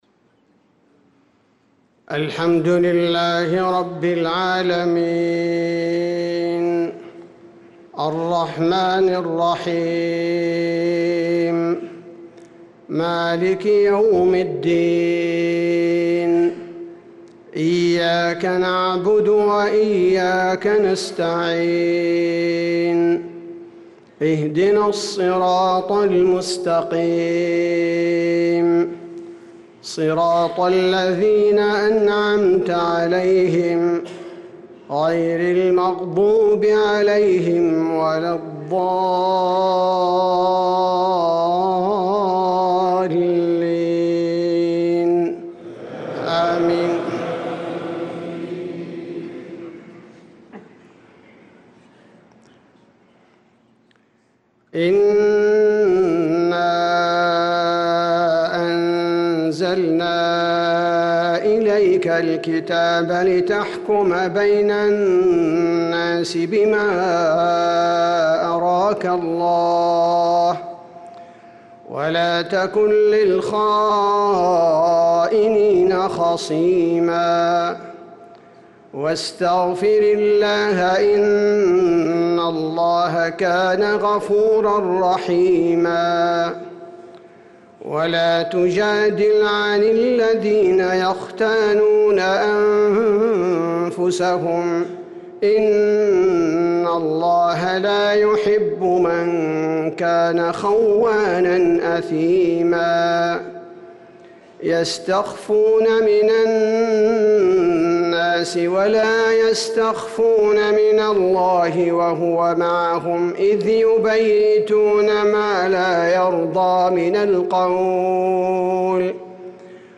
صلاة العشاء للقارئ عبدالباري الثبيتي 21 شعبان 1445 هـ
تِلَاوَات الْحَرَمَيْن .